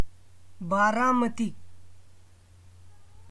pronunciation ([baːɾamət̪iː]) is a city, a tehsil and a municipal council in Pune district in the state of Maharashtra, India.[1] The city is about 100 km (62 miles) southeast of the city of Pune and about 250 km from Mumbai.